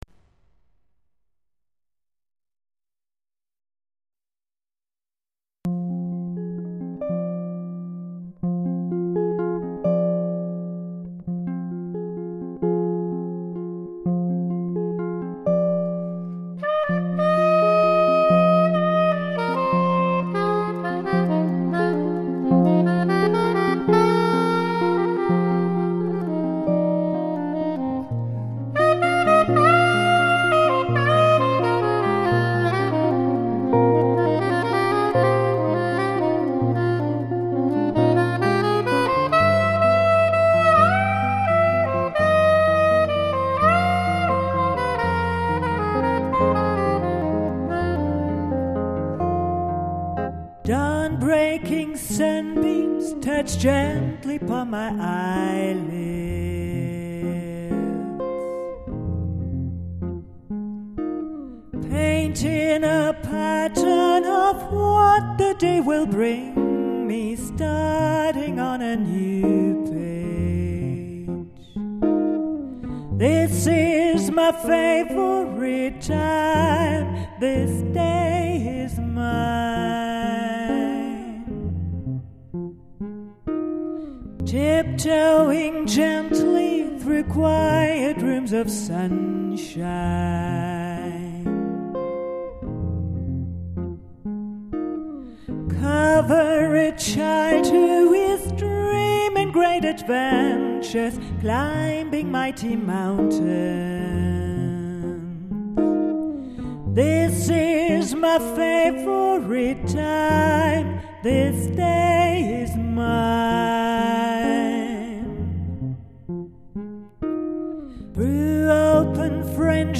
Probe (Castle, Saarburg)